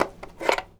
phone_pickup_handle_02.wav